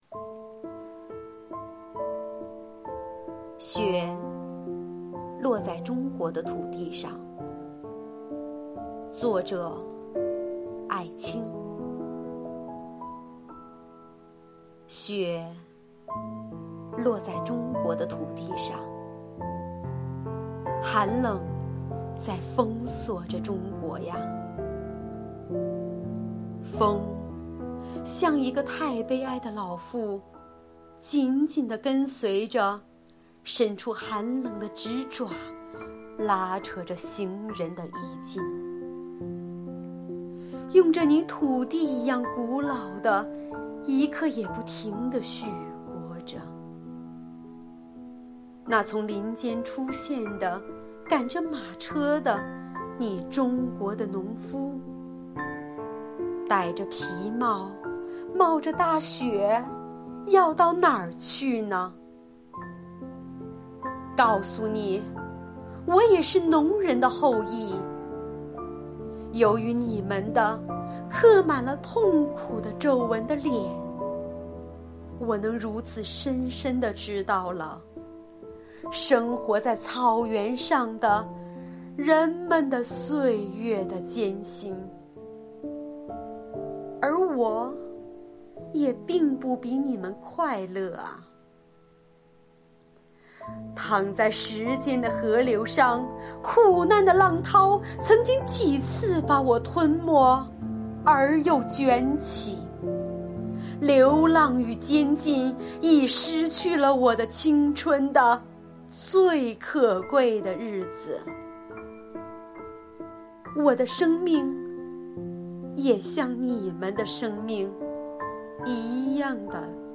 经典诵读 | 重温课本里的英雄故事之《雪落在中国的土地上》